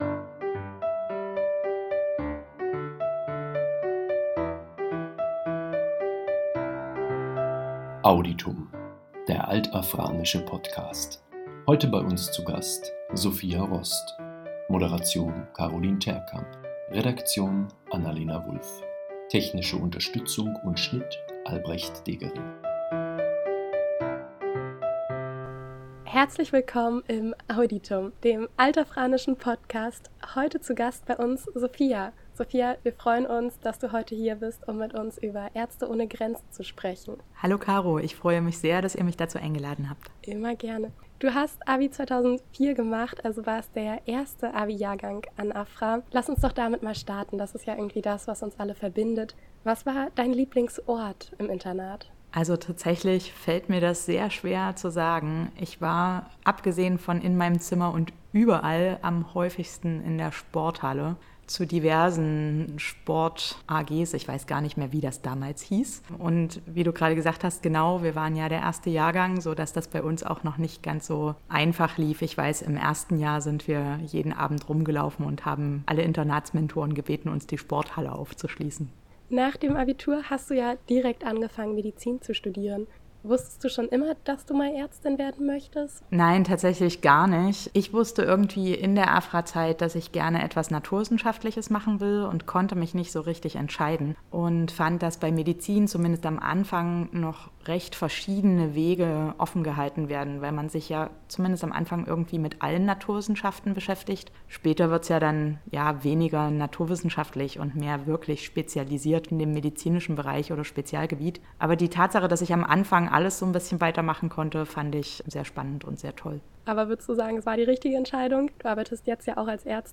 Im Interview erzählt sie von den Herausforderungen und wie sie sich auf ihre Einsätze vorbereitet hat.